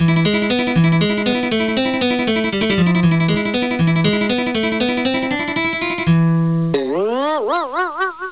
00700_Sound_guitar.mp3